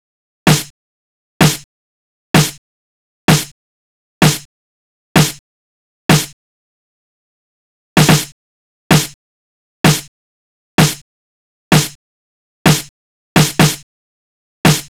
Session 11 - Snare Loop.wav